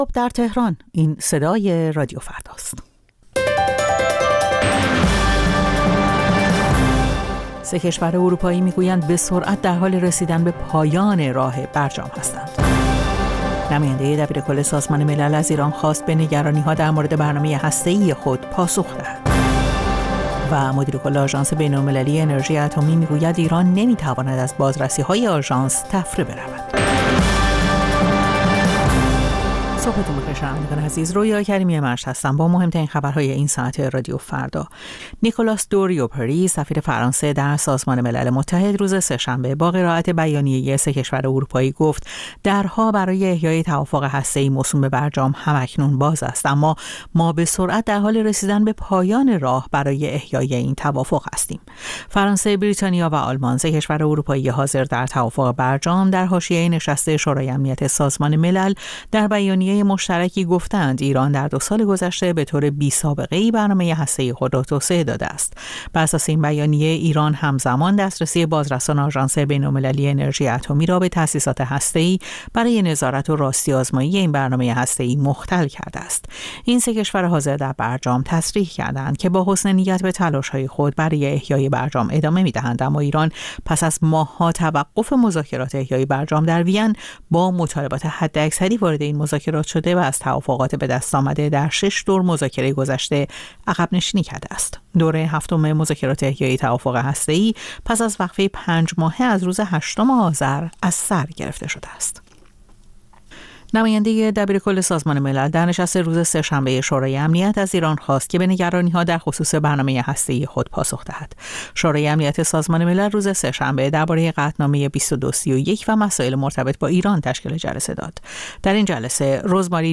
سرخط خبرها ۶:۰۰